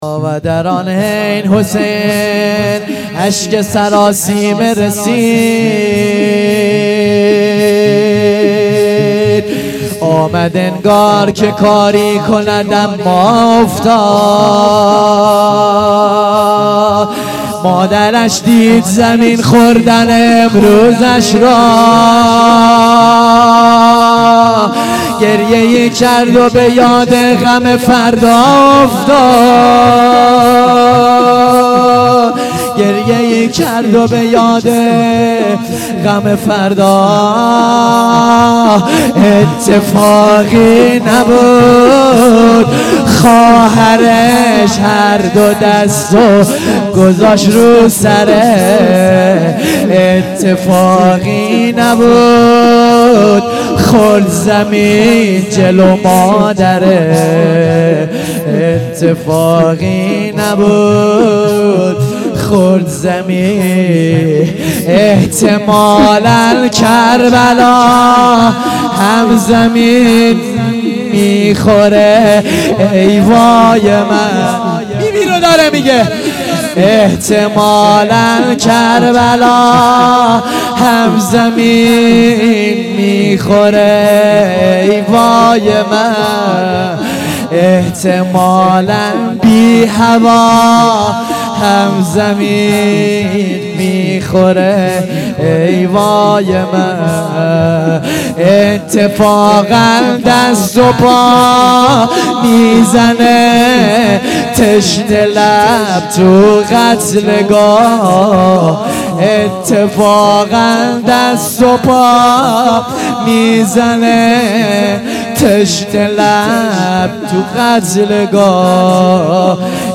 مراسم هفتگی